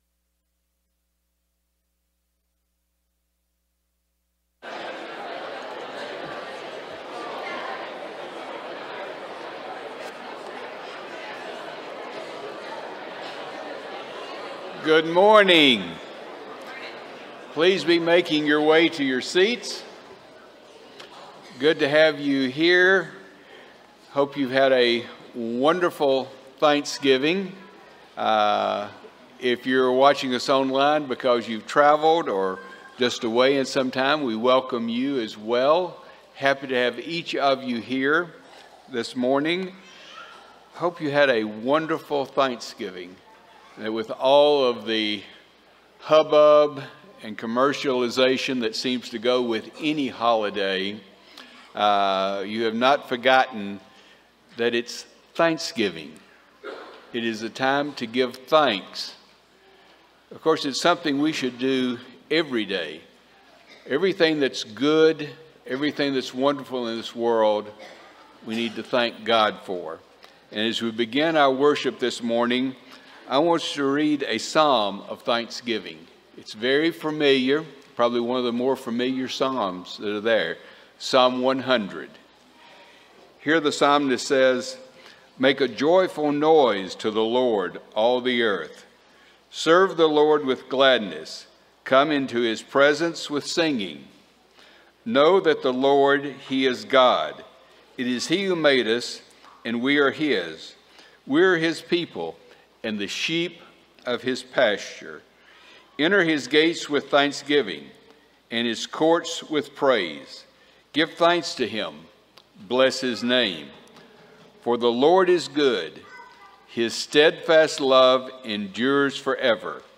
Ephesians 4:4, English Standard Version Series: Sunday AM Service